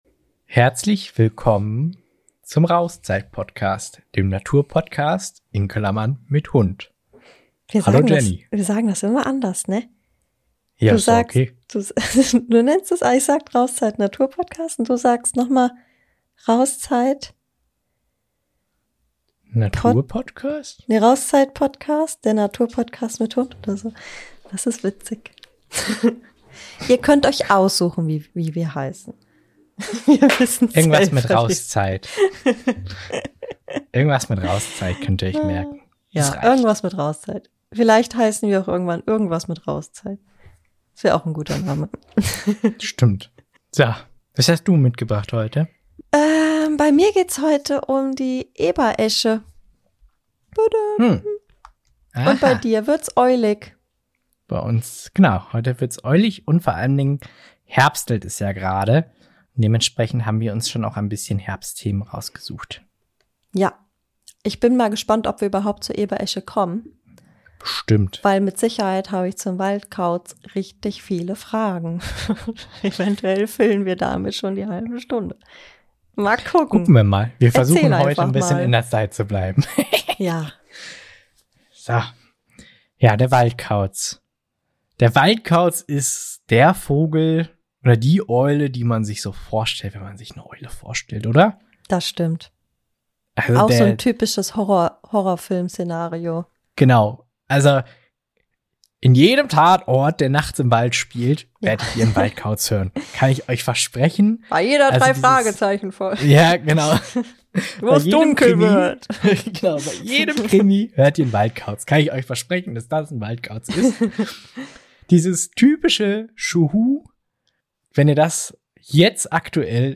In dieser Folge nehmen wir euch mit auf die letzte Pipirunde des Tages – und zwar dahin, wo der Waldkauz ruft. Wir sprechen darüber, wo ihr ihn jetzt hören (und vielleicht sogar sehen) könnt – und versuchen auch selbst, seine Rufe nachzumachen.